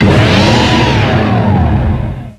Cri de Clamiral dans Pokémon X et Y.